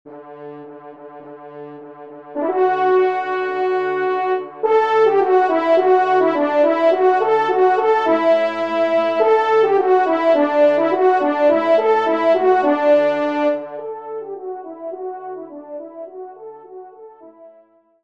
Genre :  Divertissement pour Trompe ou Cor et Piano
1e Trompe